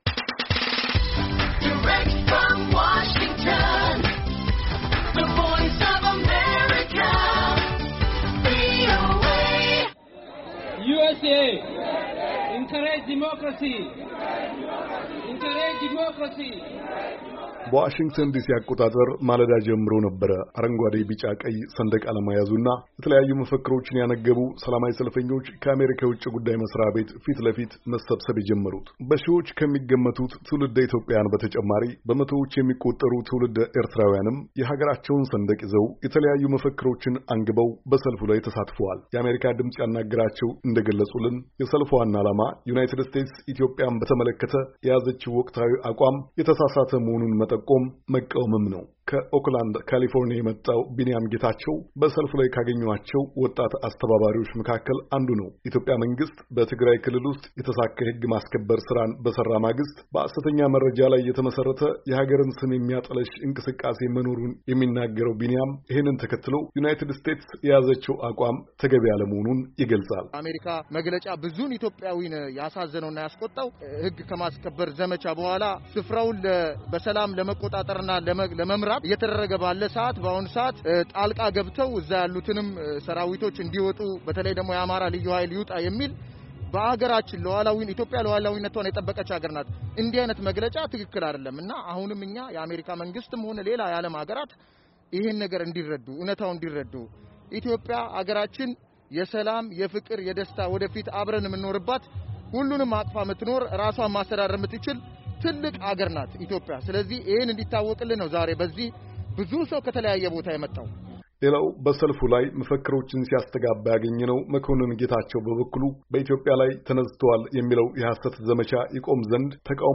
ቪኦኤ ያናገራቸው ተሳታፊዎች እንደገለጹልን የሰልፉ ዋና ዓላማ ዩናይትድ ስቴትስ ኢትዮጵያን በተመለከተ የያዘችውን ወቅታዊ አቋም የተሳሳተ መሆኑን መጠቆም፣ መቃወምም ነው።